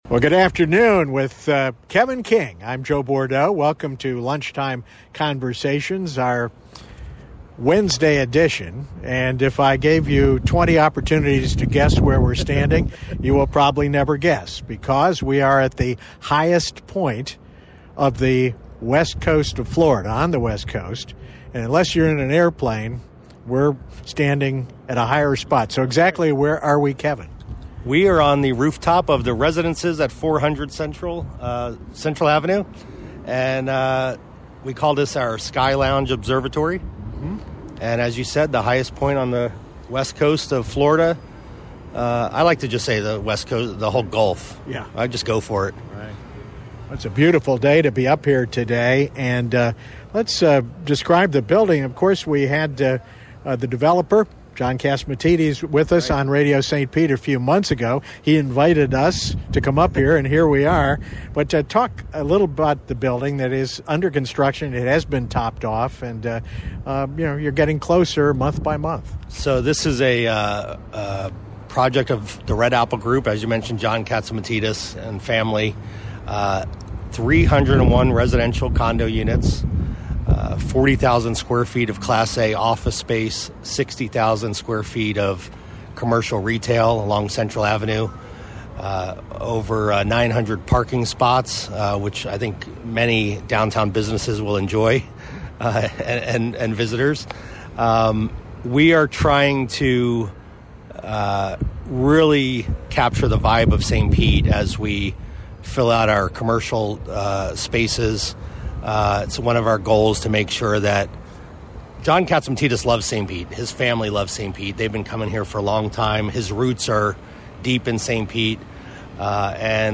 Rooftop Broadcast: Lunchtime Conversations" 2-26-25